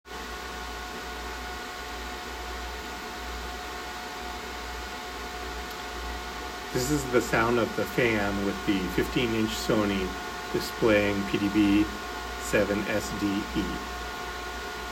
PC fan noise from 3 feet away with Nvidia 3070 when using Sony SR1 display.
Not related to the initial zoom size, but the fan on the Windows 11 PC with Nvidia 3070 graphics cranks up top a deafening full speed whenever a connection is made to the Sony SR1 display. The display is unusable the volume is so loud.
Sony SR1 display noise.m4a